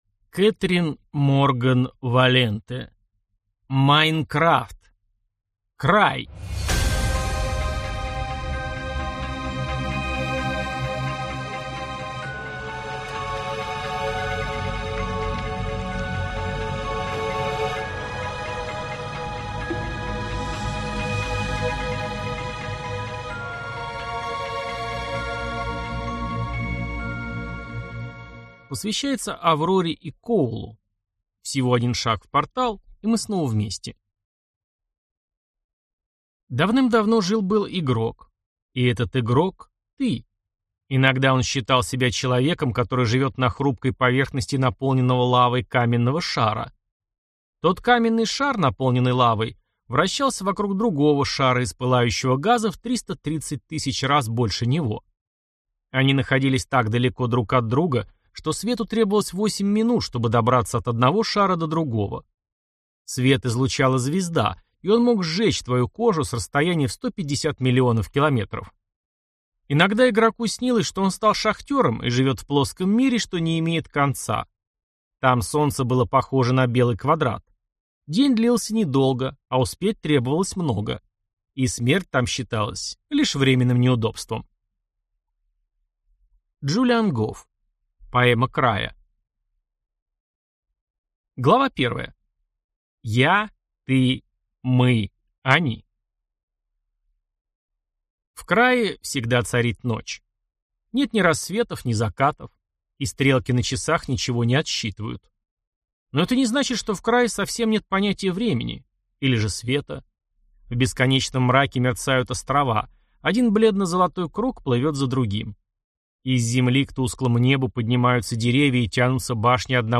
Аудиокнига Minecraft: Край | Библиотека аудиокниг